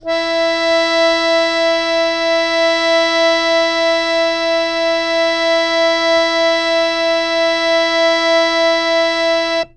interactive-fretboard / samples / harmonium / E4.wav
E4.wav